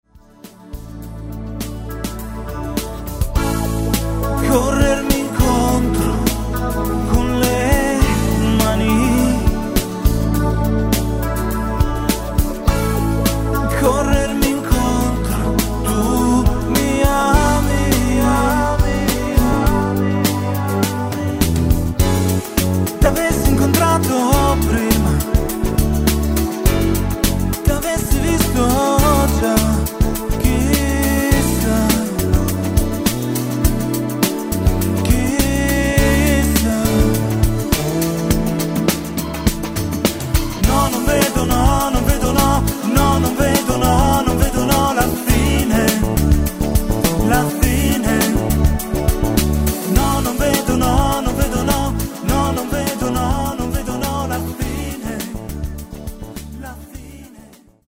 musica pop italiana